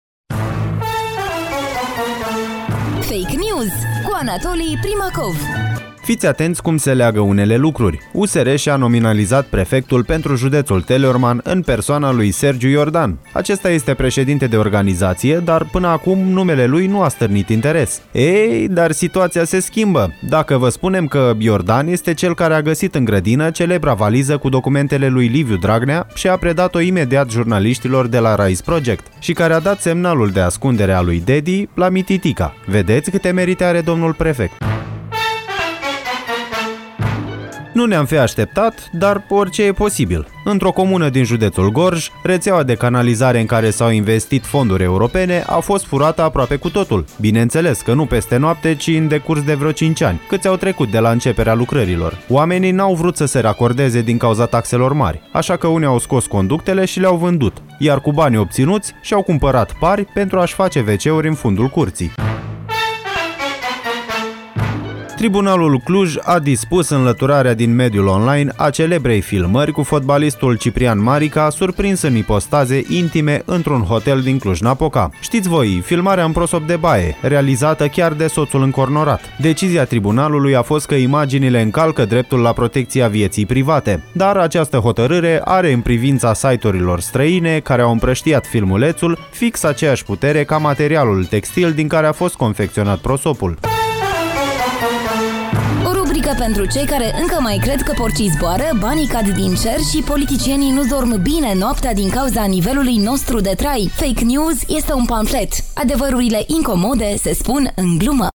Genul programului: pamflet.